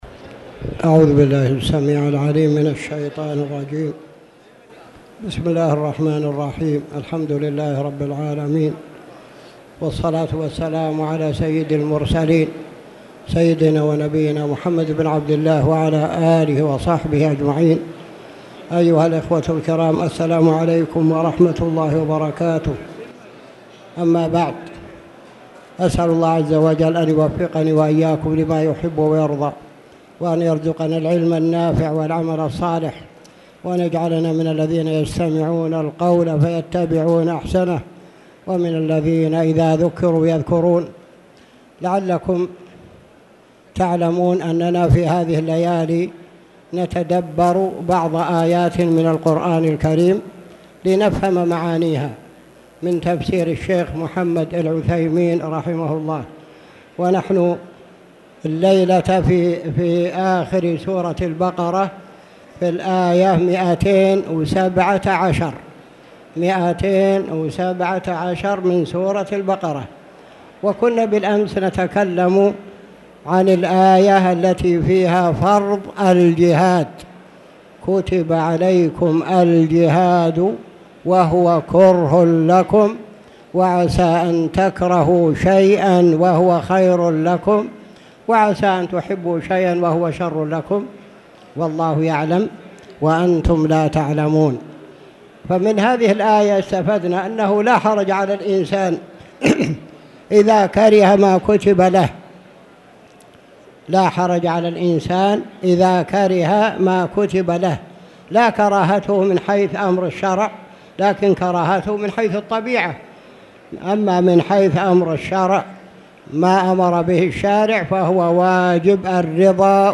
تاريخ النشر ٩ رمضان ١٤٣٧ هـ المكان: المسجد الحرام الشيخ